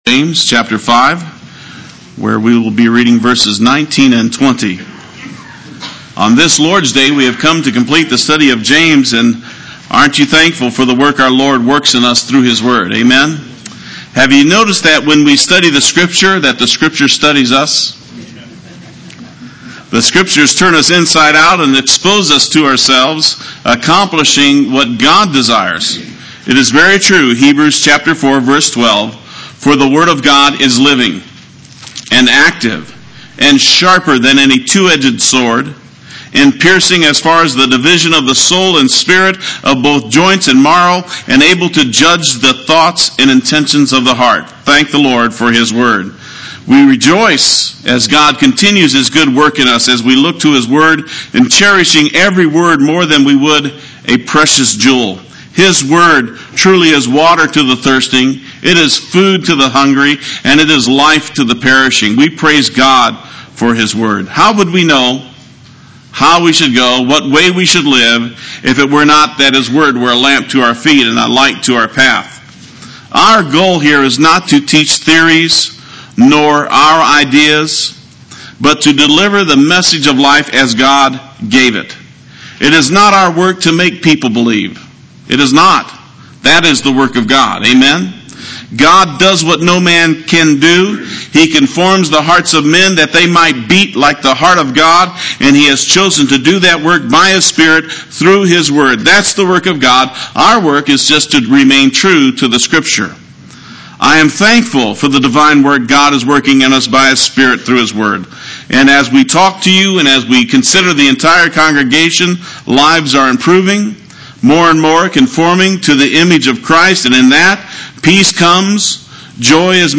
Play Sermon Get HCF Teaching Automatically.
Save His Soul Sunday Worship